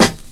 j dilla snare 2.wav